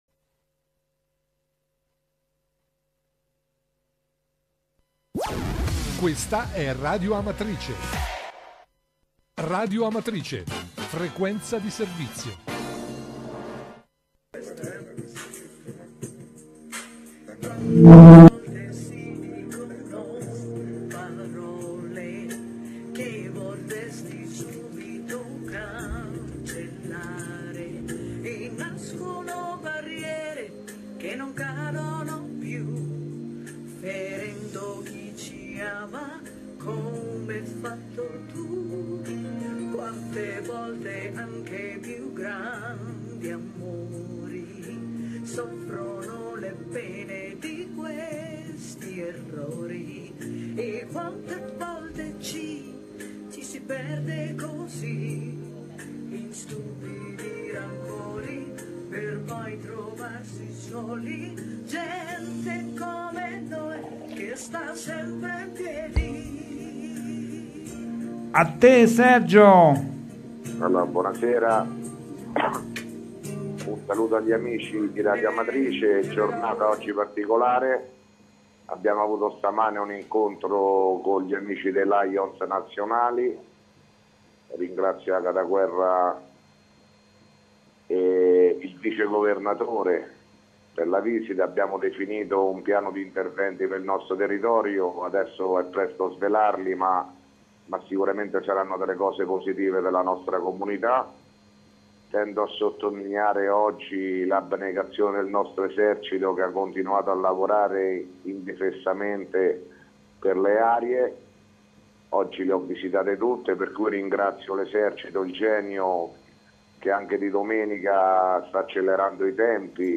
Di seguito il messaggio audio del Sindaco Sergio Pirozzi, del 19 febbraio 2017